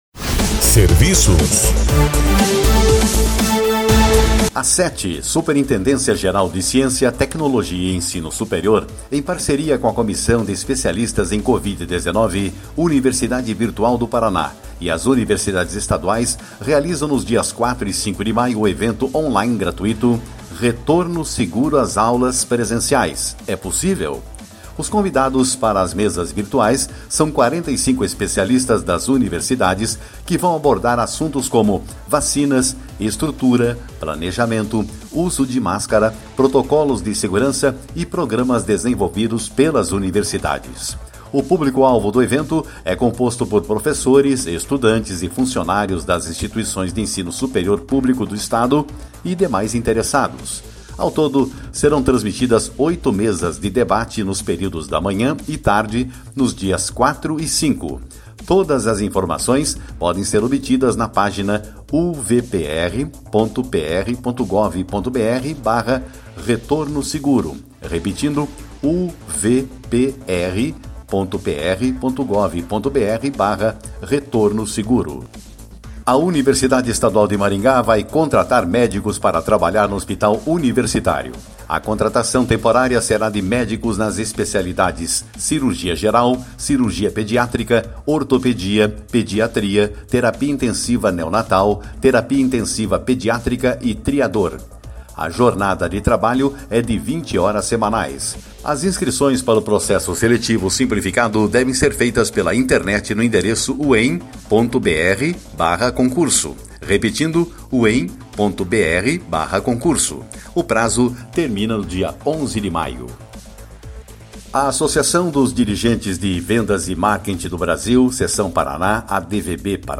boletim de serviços